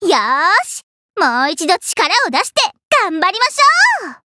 文件 文件历史 文件用途 全域文件用途 Ja_Fifi_fw_01.ogg （Ogg Vorbis声音文件，长度4.2秒，102 kbps，文件大小：53 KB） 源地址:游戏语音 文件历史 点击某个日期/时间查看对应时刻的文件。 日期/时间 缩略图 大小 用户 备注 当前 2018年5月25日 (五) 02:12 4.2秒 （53 KB） 地下城与勇士  （ 留言 | 贡献 ） 分类:祈求者比比 分类:地下城与勇士 源地址:游戏语音 您不可以覆盖此文件。